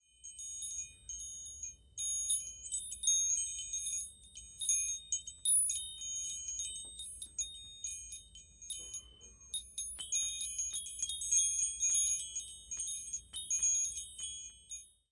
描述：竹风铃在录音室录制。
标签： 风铃
声道立体声